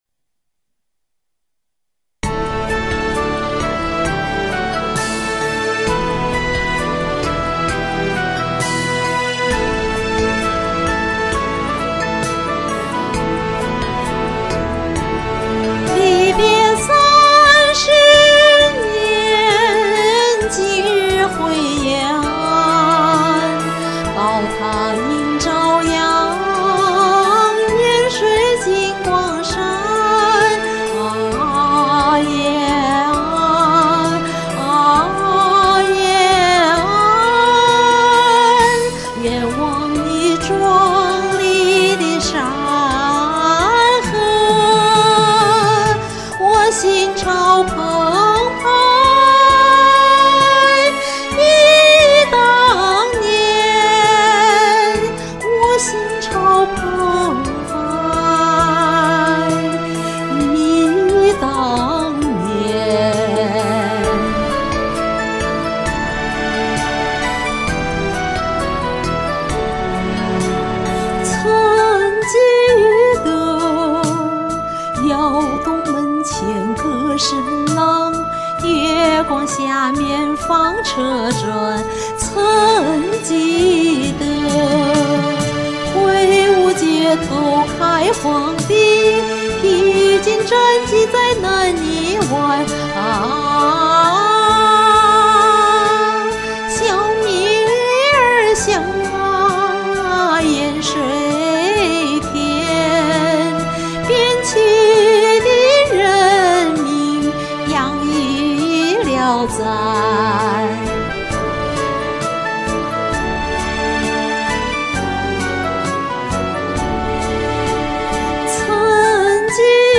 经典老红歌
具有鲜明的陕北民间音乐风格
情感丰富，特色鲜明